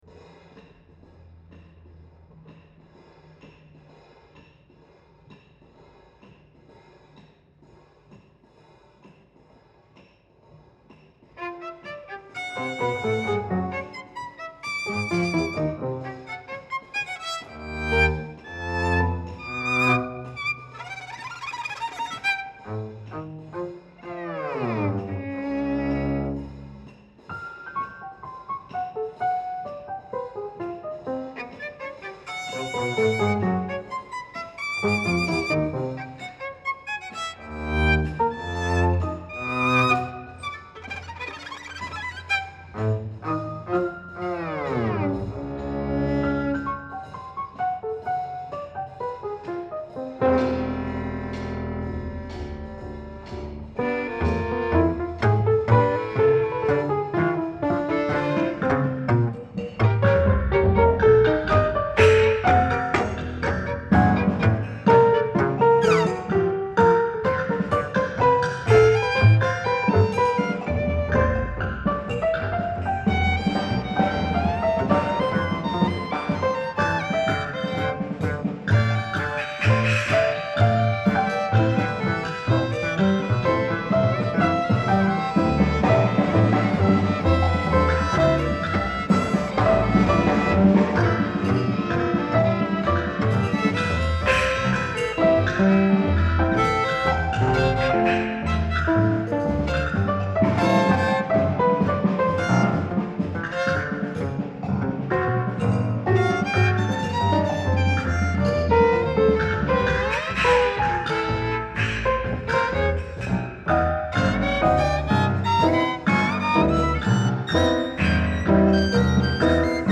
originele Tibetaanse langeafstands-hoorn